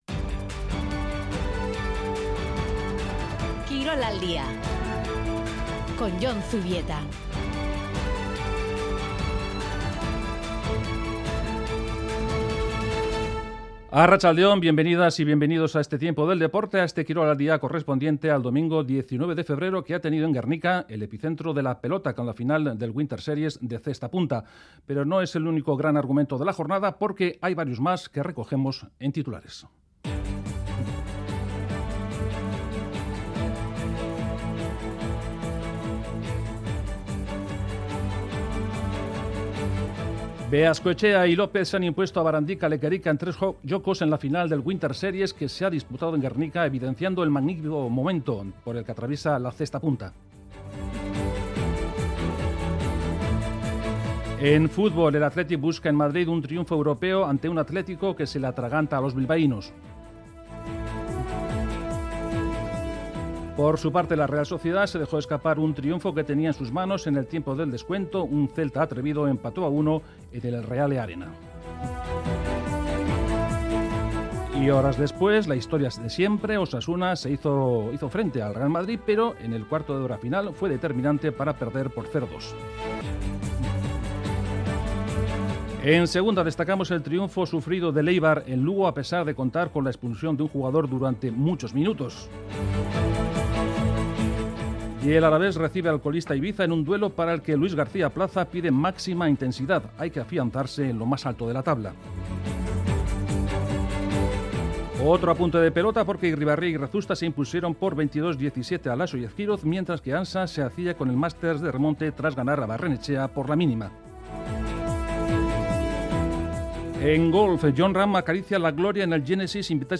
Informativo de actualidad deportiva